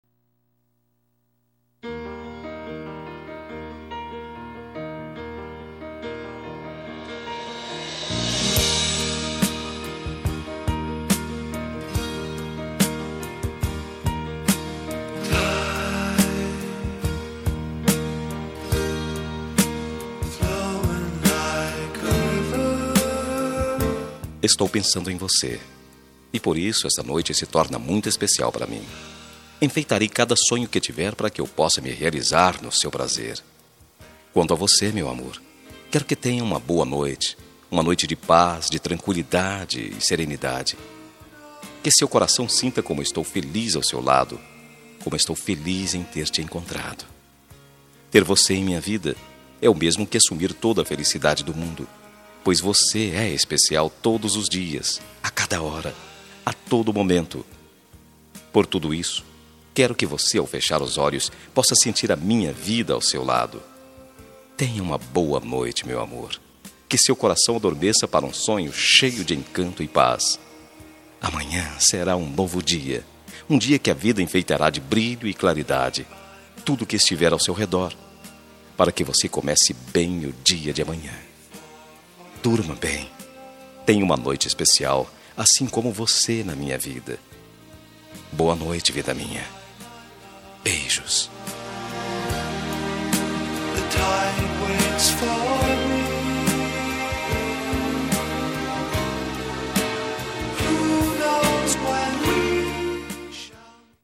Telemensagem de Boa Noite – Voz Masculina – Cód: 63240 – Geral